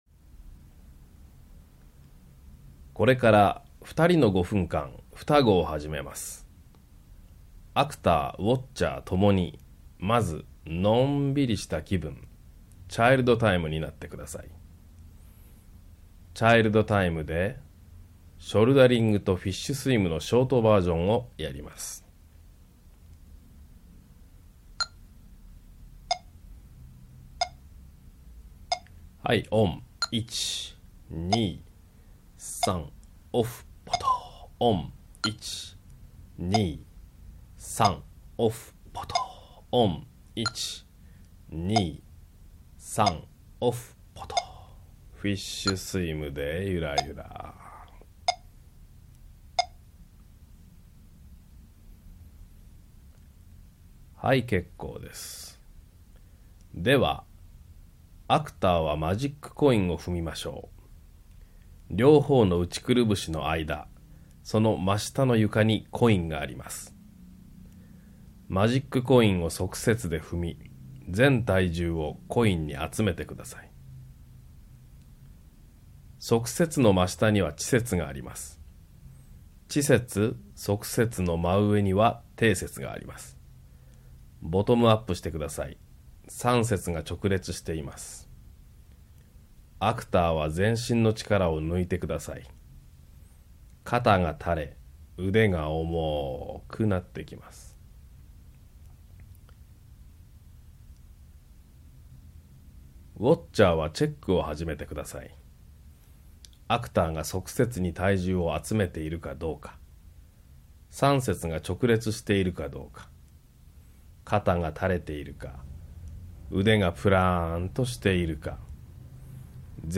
【独習用MP3音源】
＜リードメッセージ＞